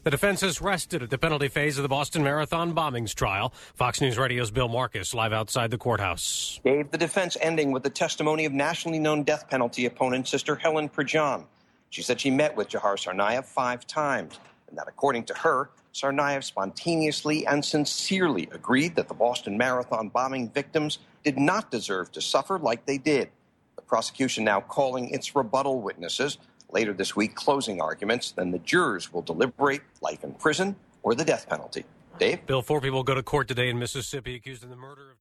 11AM LIVE
May-11-LIVE-11AM-DEFENSE-RESTS.mp3